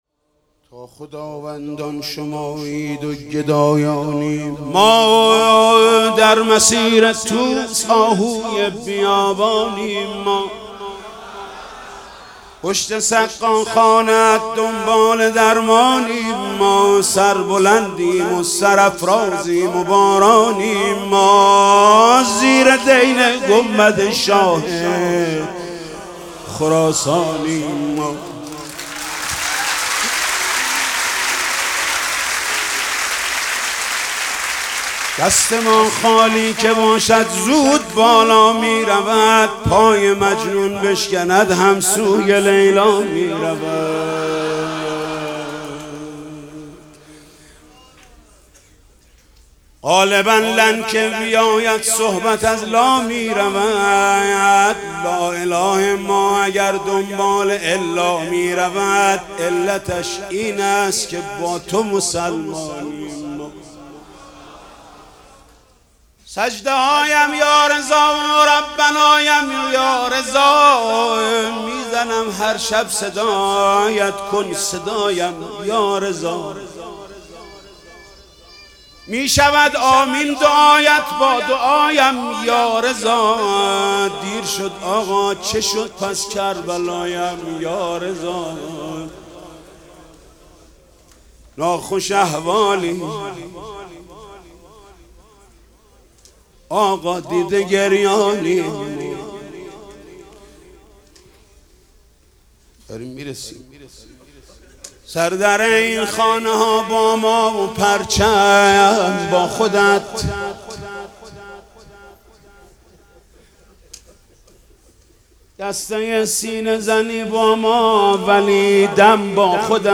مدیحه سرایی و مداحی حاج محمود کریمی در شب میلاد امام رضا (ع) را بشنوید.